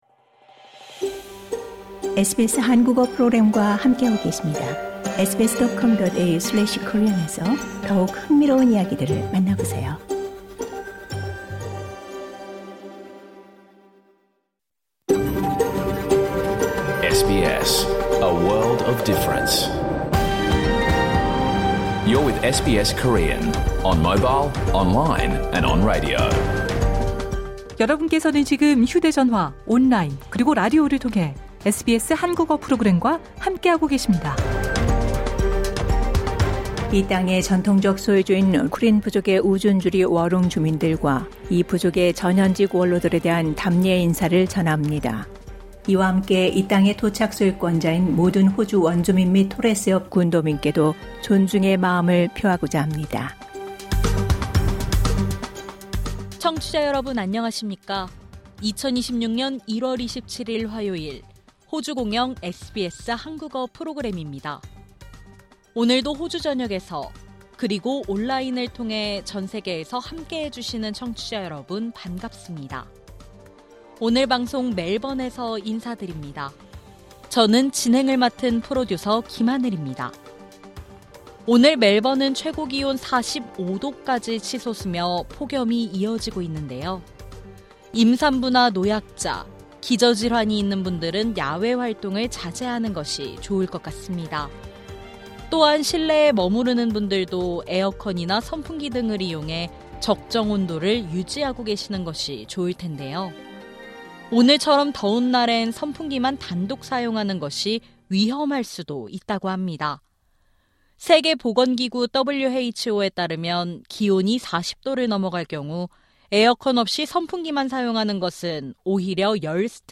2026년 1월 27일 화요일에 방송된 SBS 한국어 프로그램 전체를 들으실 수 있습니다.